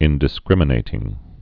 (ĭndĭ-skrĭmə-nātĭng)